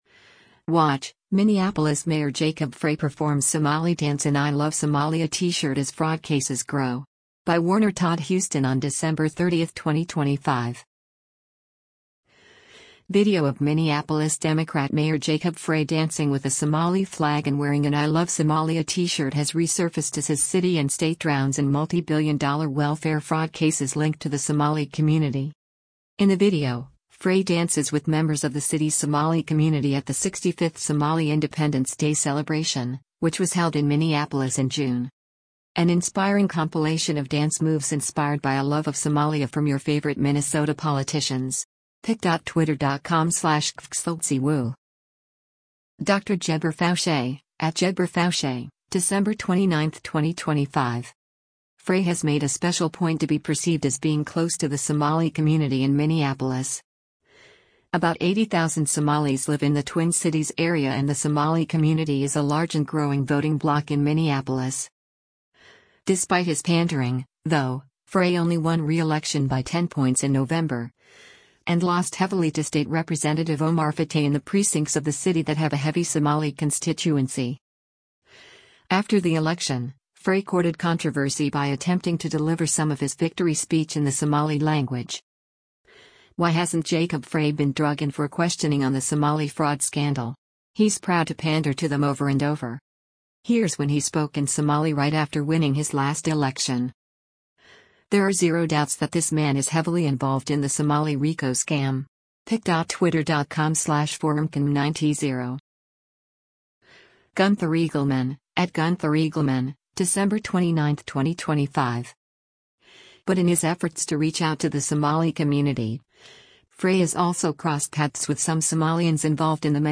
In the video, Frey dances with members of the city’s Somali community at the 65th Somali Independence Day celebration, which was held in Minneapolis in June.